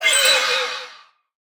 Minecraft Version Minecraft Version 25w18a Latest Release | Latest Snapshot 25w18a / assets / minecraft / sounds / mob / allay / item_taken4.ogg Compare With Compare With Latest Release | Latest Snapshot
item_taken4.ogg